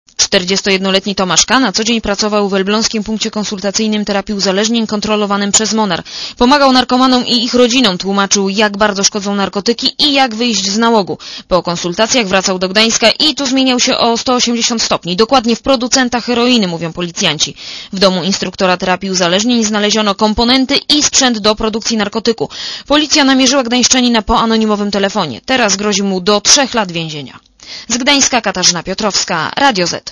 Posłuchaj relacji reporterki Radia ZET (117 KB)